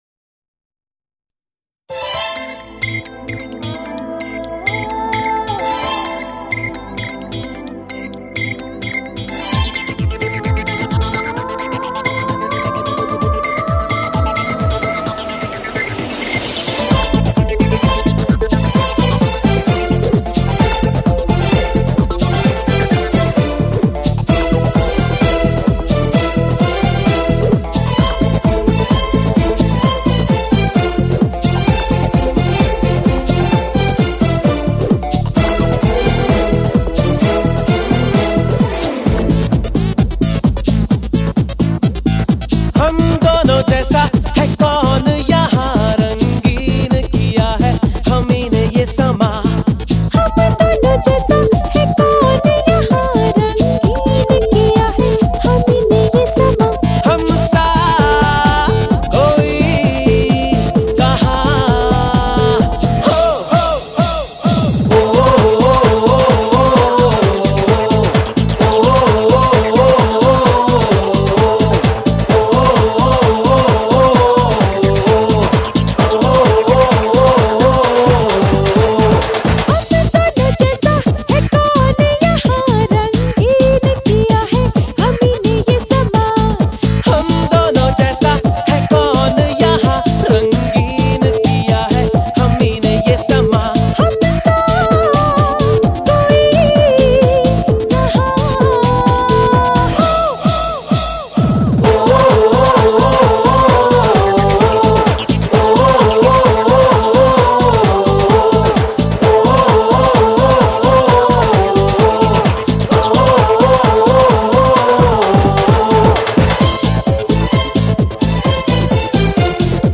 Home » Bollywood Mp3 Songs » Bollywood Movies